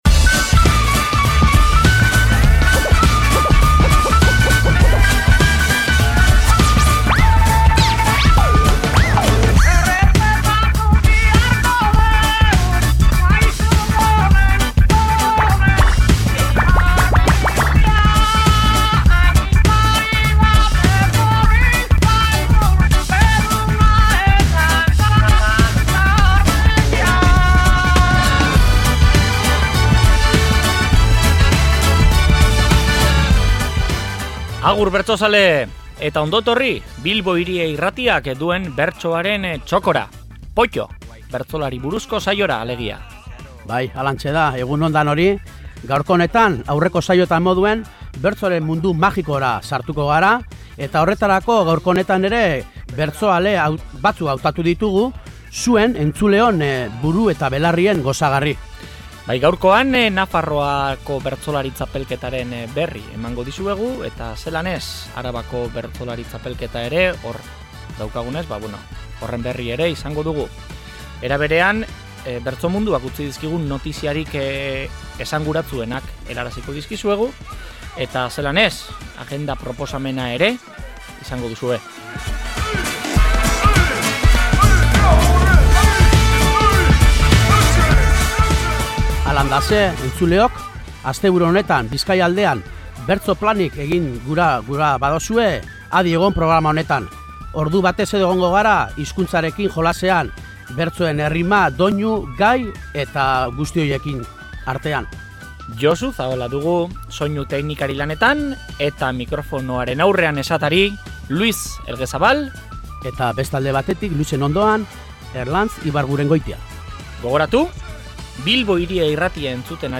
Bertan, momentuko bertso txapelketen jarraipena, bertsoak entzun, komentatu eta agenda mardula aurkituko duzue.